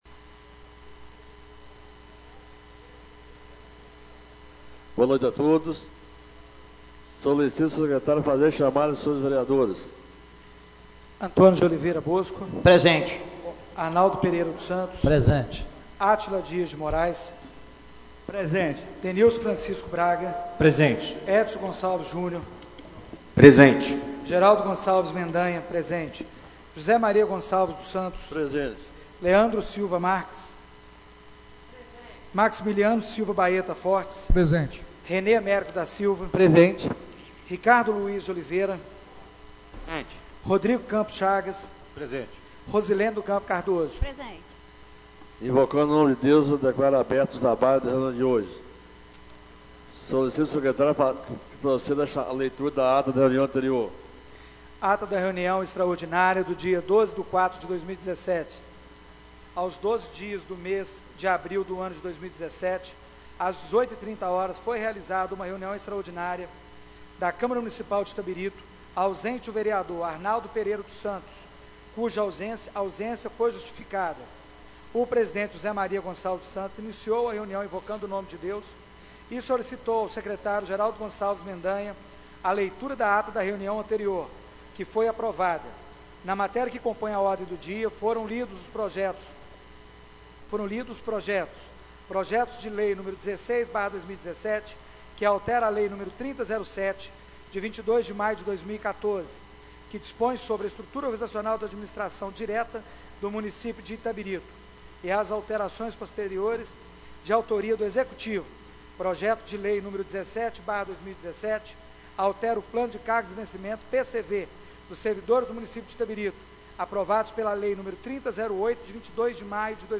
Reunião Ordinária do dia 17/04/2017